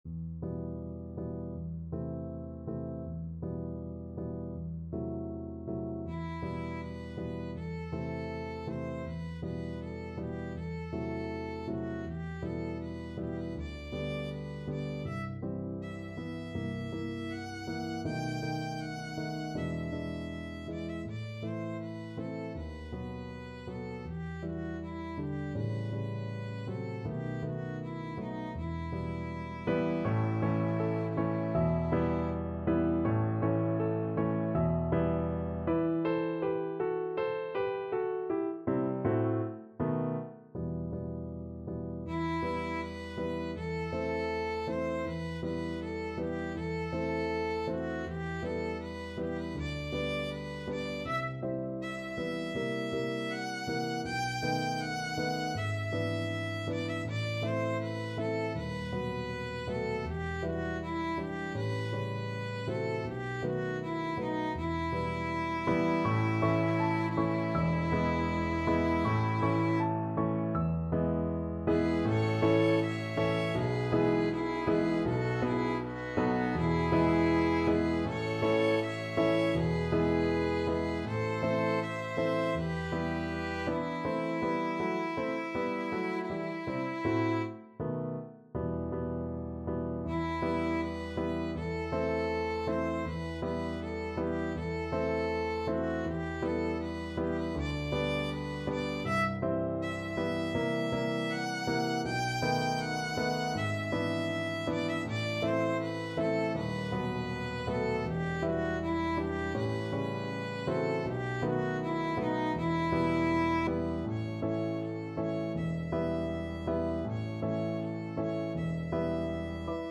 2/4 (View more 2/4 Music)
~ = 100 Andante
Classical (View more Classical Violin Music)